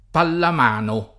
vai all'elenco alfabetico delle voci ingrandisci il carattere 100% rimpicciolisci il carattere stampa invia tramite posta elettronica codividi su Facebook pallamano [ pallam # no o pallamm # no ] (raro palla a mano [ p # lla a mm # no ]) s. f. (sport.)